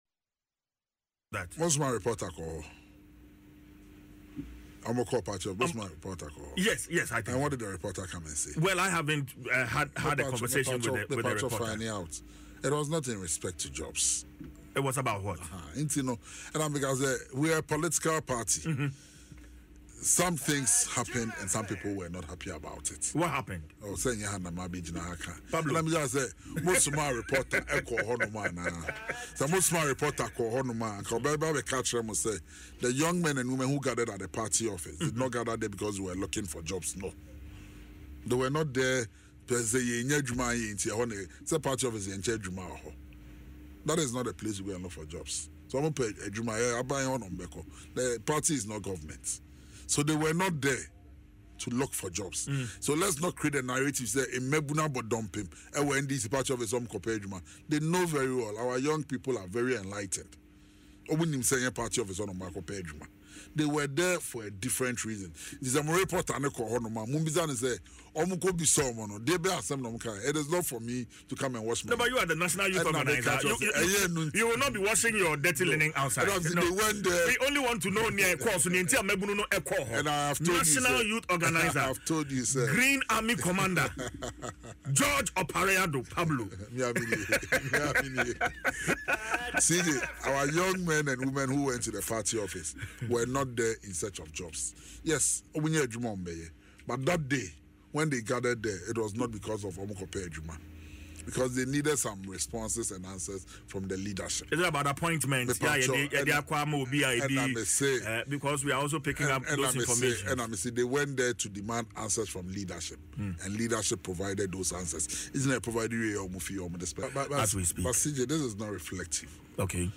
Opare Addo, speaking on Adom FM’s Dwaso Nsem, acknowledged the need for jobs but emphasized that the protesters’ main purpose was to demand answers from the party leadership.